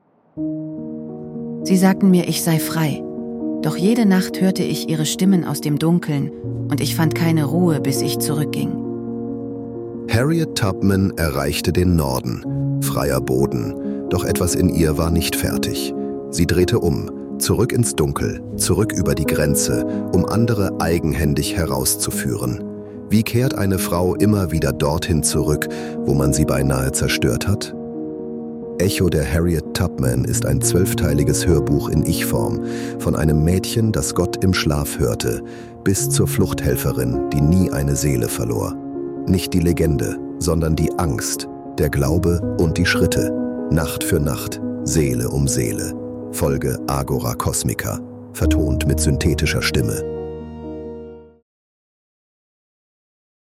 In Mensch–KI-Kollaboration erstellt.
Wir nutzen synthetische Stimmen, damit diese Geschichten